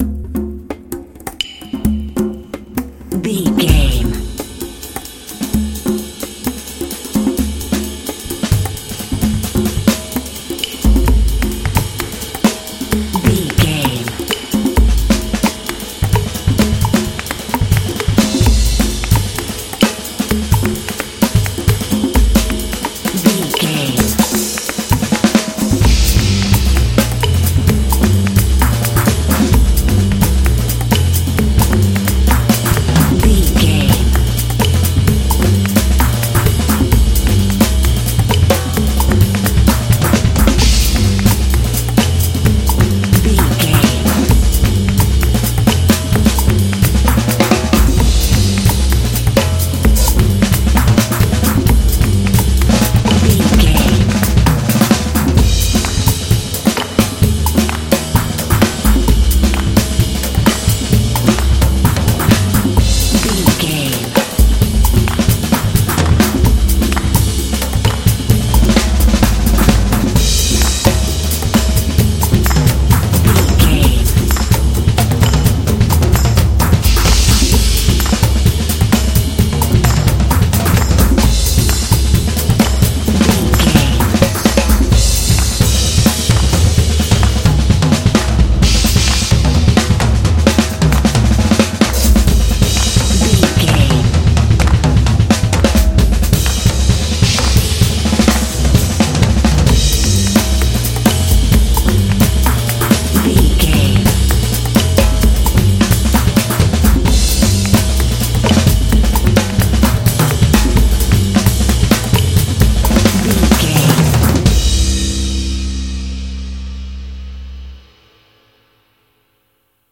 Mixolydian
groovy
percussion
double bass
jazz
jazz drums